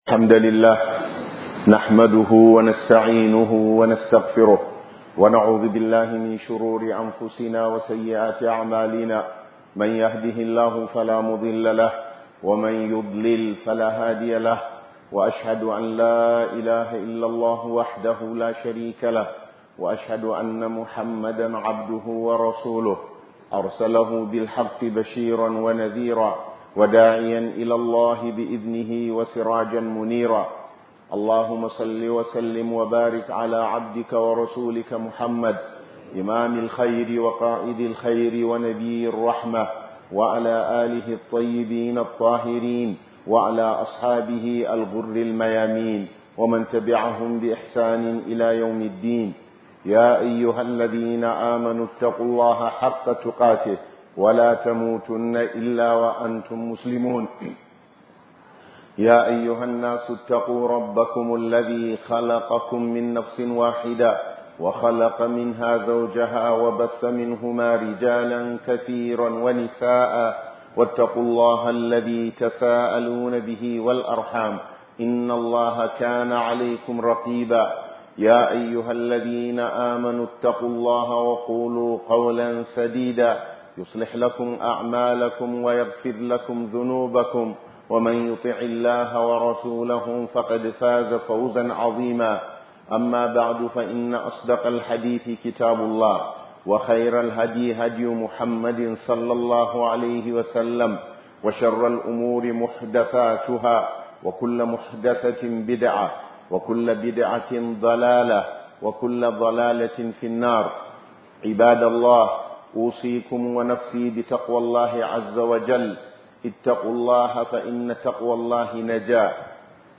Tunatarwa Daga Cikin Surah Al-Hujrat - 2026-04-24 - HUDUBA